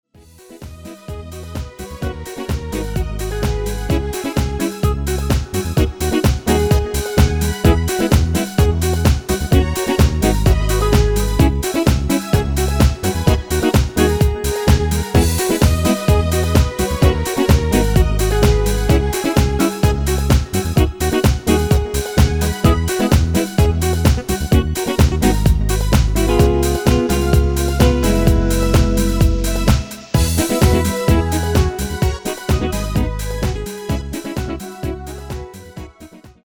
Demo/Koop midifile
Genre: Pop & Rock Internationaal
Toonsoort: G#s
- Vocal harmony tracks
Demo = Demo midifile